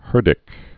(hûrdĭk)